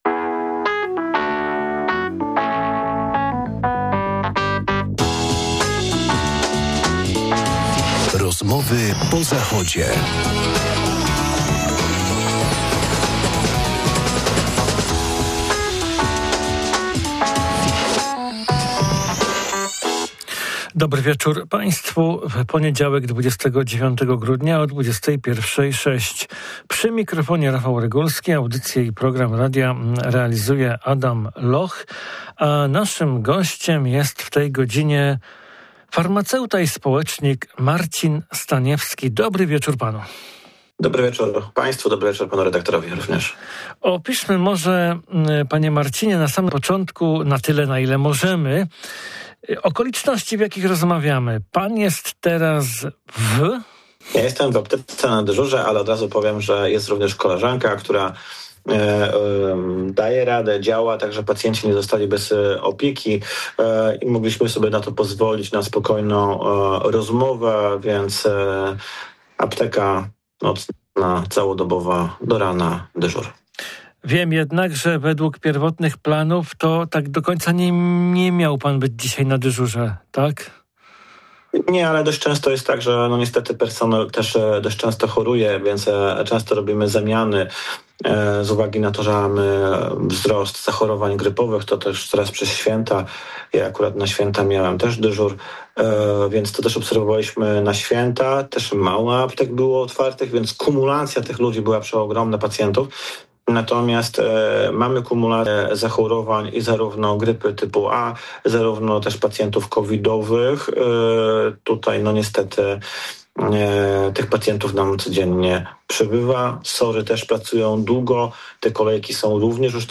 Posłuchajcie rozmowy o zupach, chlebie, agregatach prądotwórczych, butach, internecie...